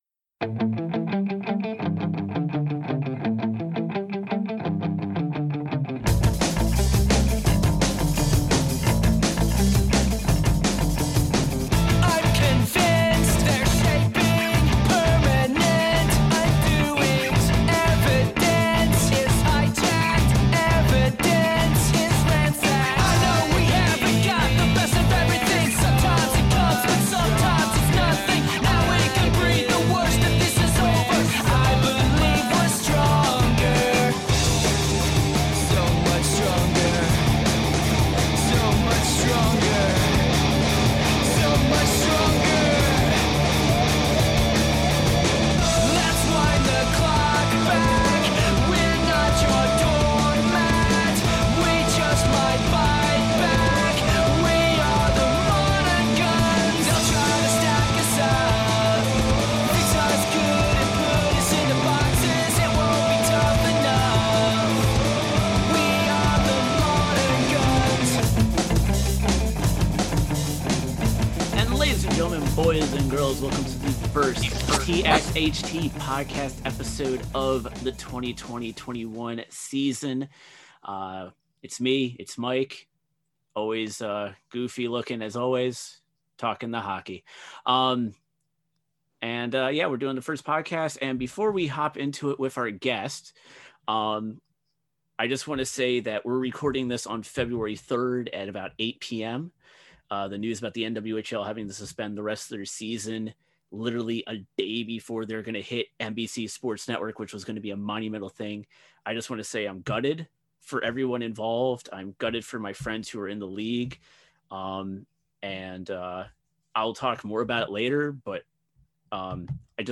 TXHT Podcast – A Conversation